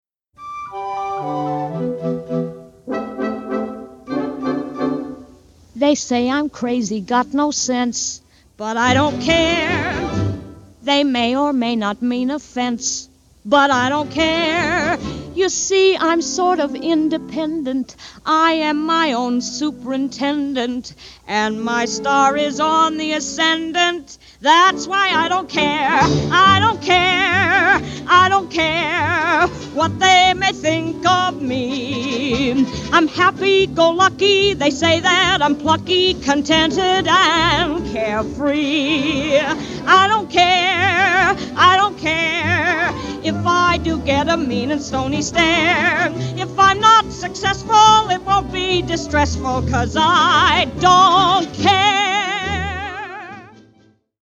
* Stereo Debut